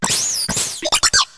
shroodle_ambient.ogg